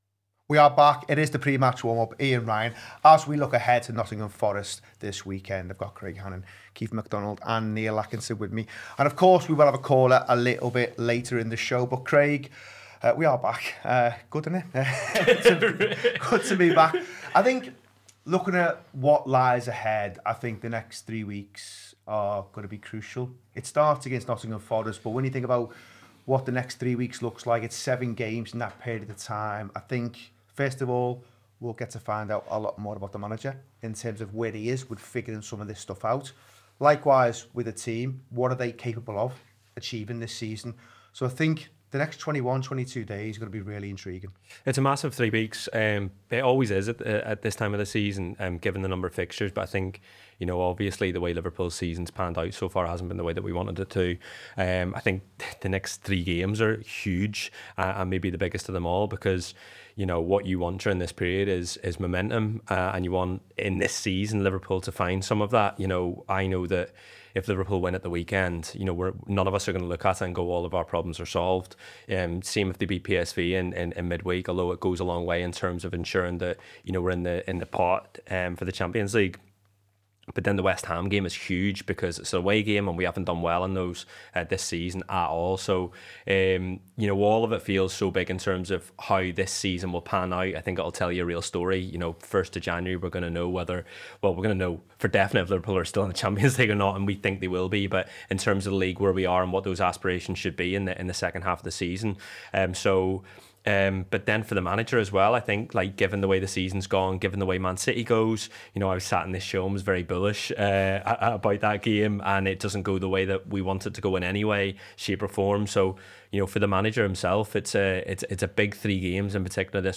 Below is a clip from the show – subscribe for more pre-match build up around Liverpool v Nottingham Forest…